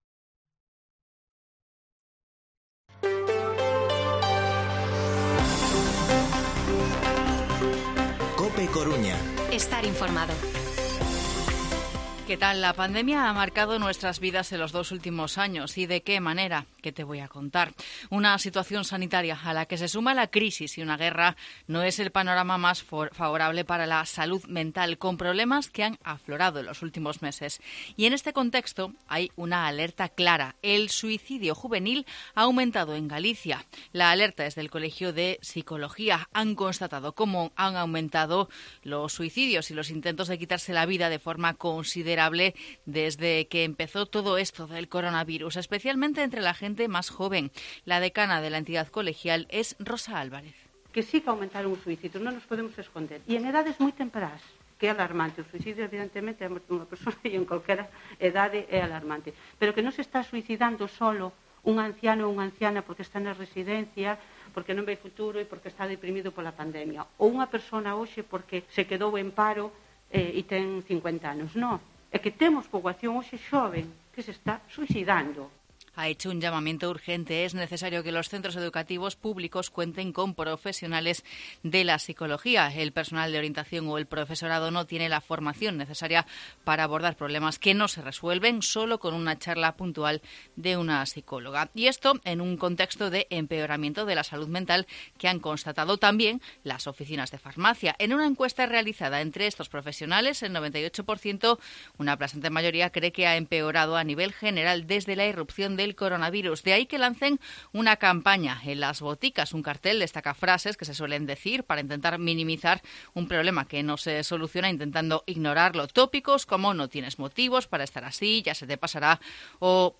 Informativo Mediodía COPE Coruña martes, 5 de abril de 2022 14:20-14:30